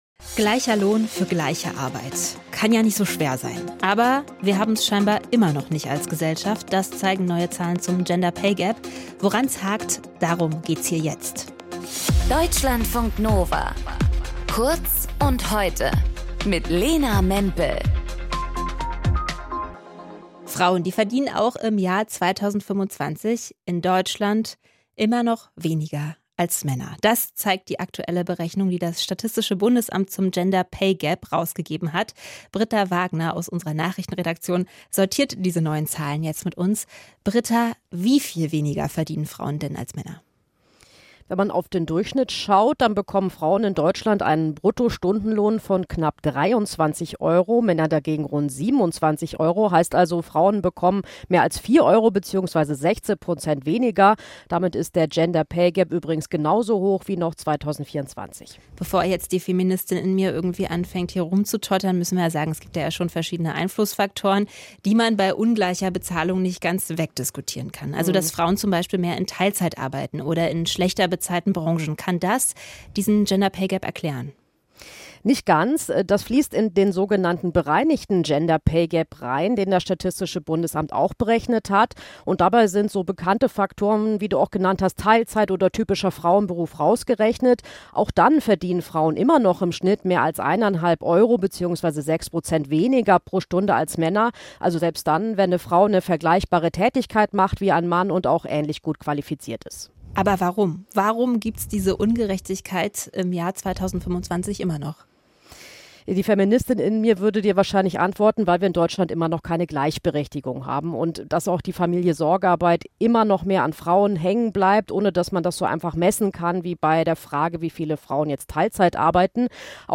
Moderatorin: